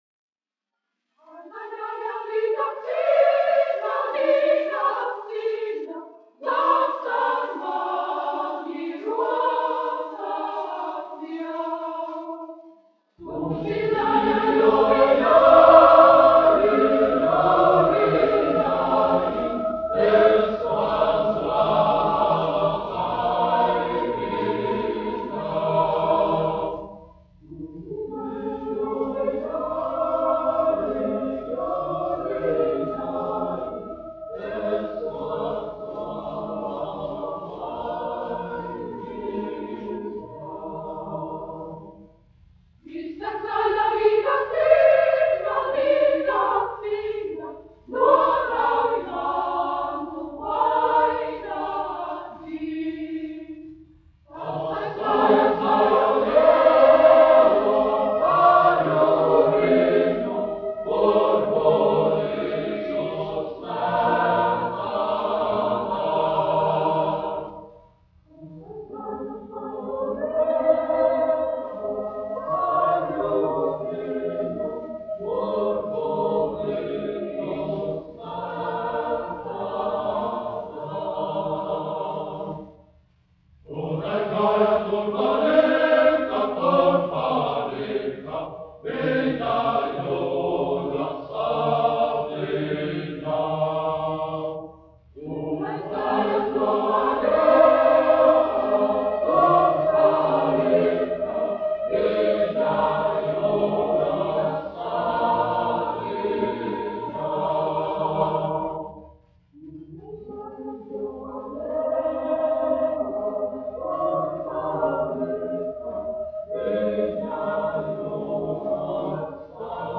Daugava (koris : Geesthacht), izpildītājs
1 skpl. : analogs, 78 apgr/min, mono ; 25 cm
Latviešu tautasdziesmas
Kori (jauktie)
Skaņuplate